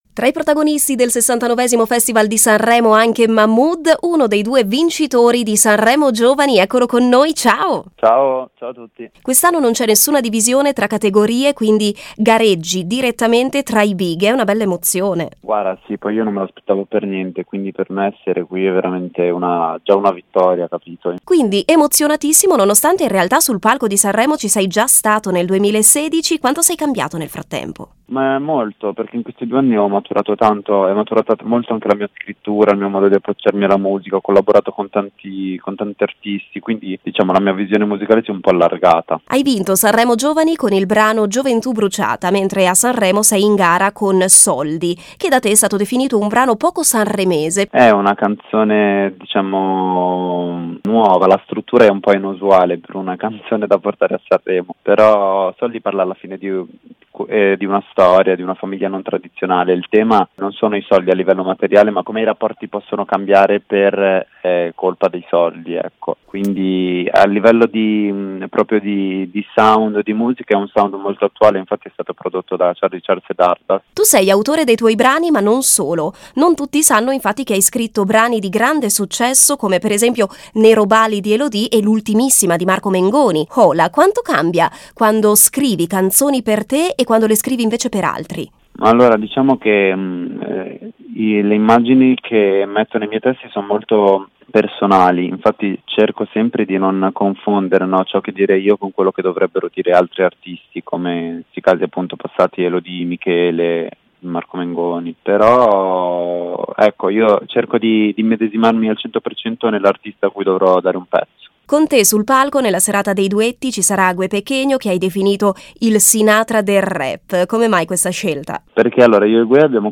SANREMO 2019: LA NOSTRA INTERVISTA A MAHMOOD